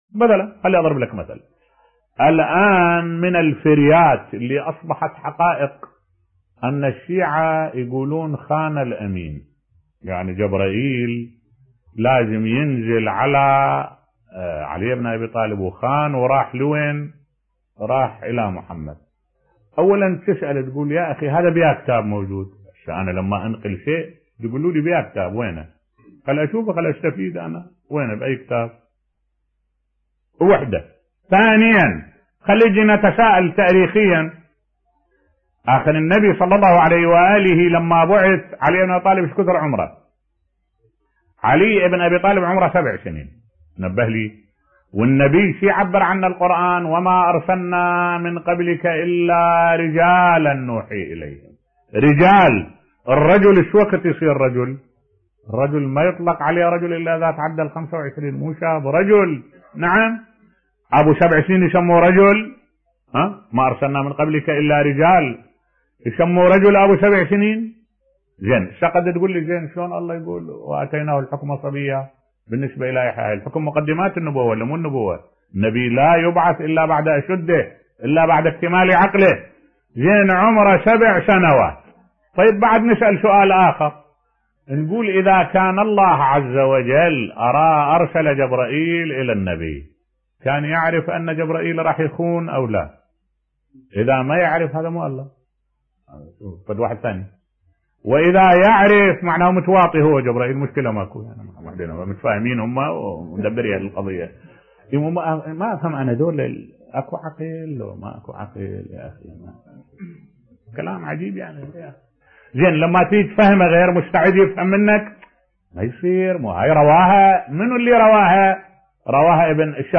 ملف صوتی تفنيد مقولة خان الأمين بصوت الشيخ الدكتور أحمد الوائلي